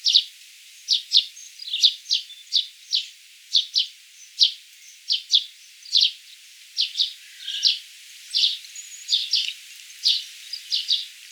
PASSER DOMESTICUS ITALIAE - ITALIAN SPARROW - PASSERA D'ITALIA
E 11° 49' - ALTITUDE: +20 m. - VOCALIZATION TYPE: calls near the nest. - SEX/AGE: unknown - COMMENT: Most birds are perched near their nests.